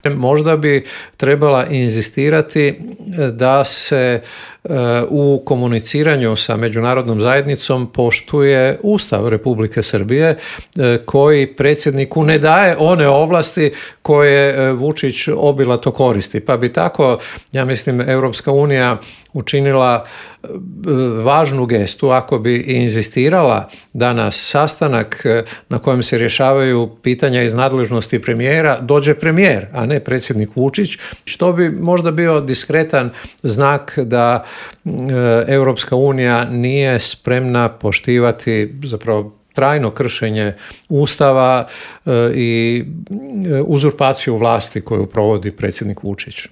Zašto su izbori na Tajvanu prijetnja svjetskom miru i zašto su izgledi za okončanja rata u Ukrajini ove godine mali, pitanja su na koje smo odgovore tražili u intervuju Media servisa s analitičarom Božom Kovačevićem.